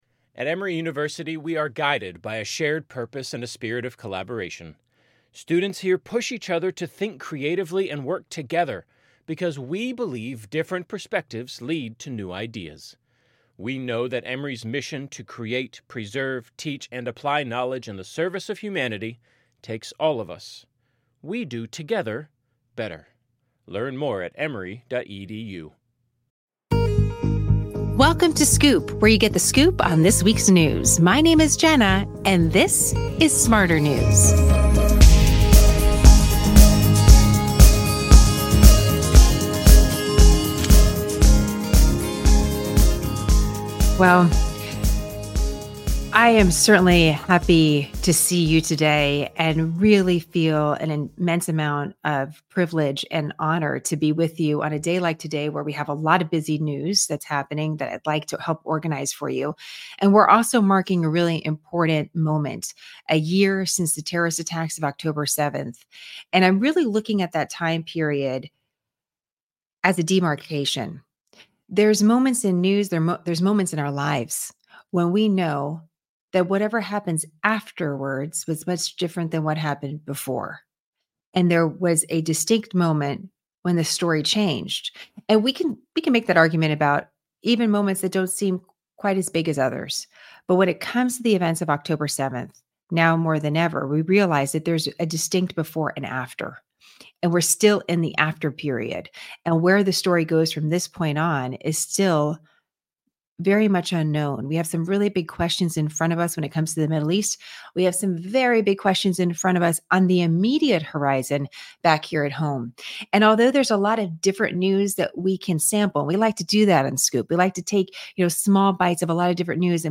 ✓ 32:00: We see an excerpt from a recent CBS Interview, where a journalist interviews archivists in both Israel and Gaza.